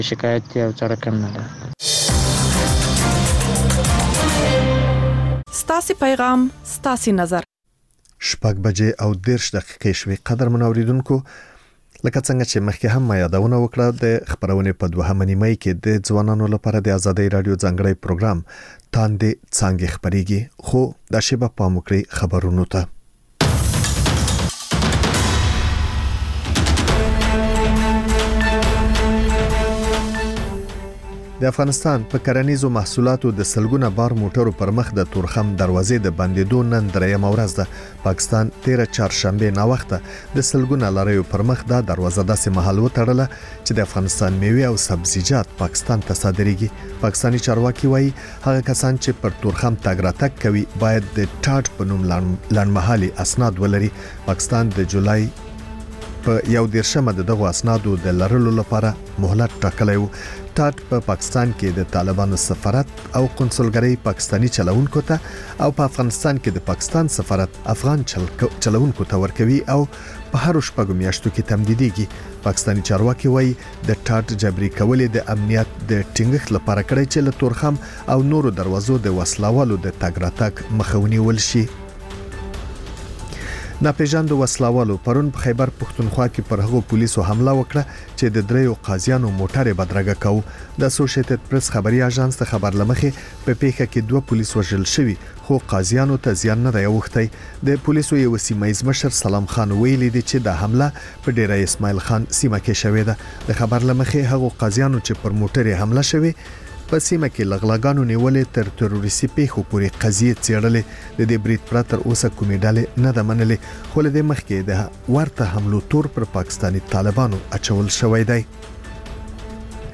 لنډ خبرونه - تاندې څانګې (تکرار)